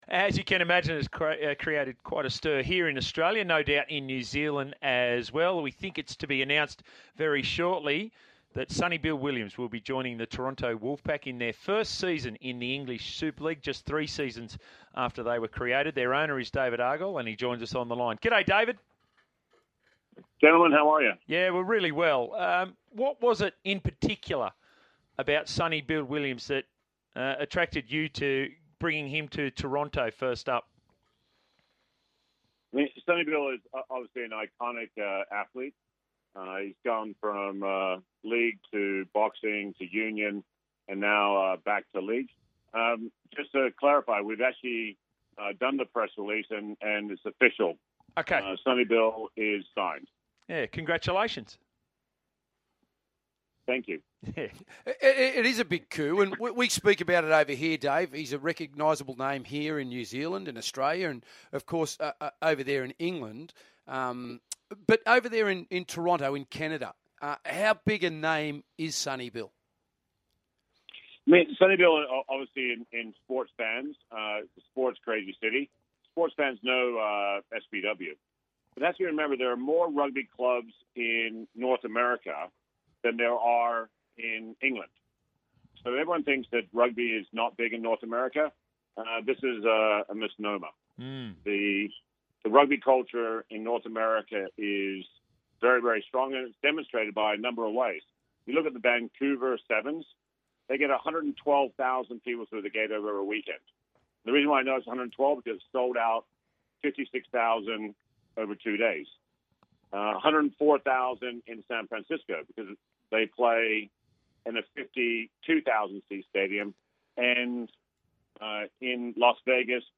And in an interview with Sky Sports Racing 's Big Sports Breakfast